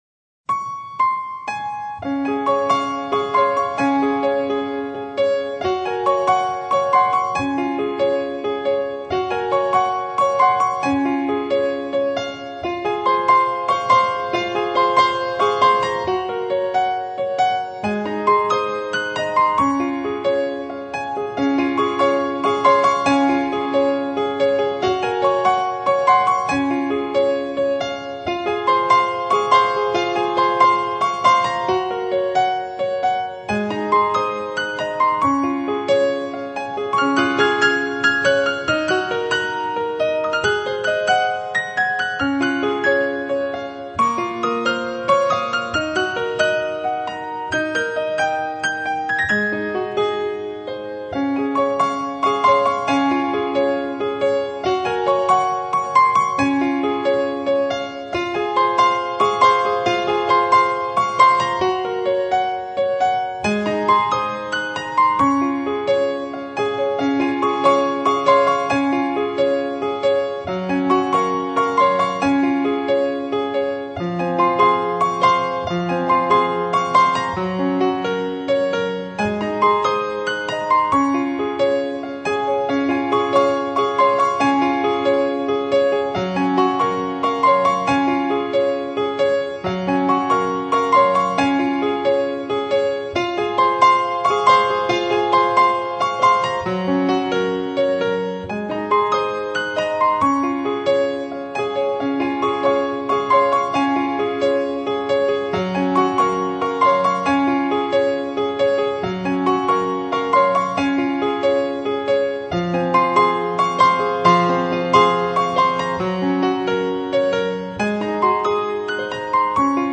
曲风缓慢恬静，带着丝丝淡淡的忧伤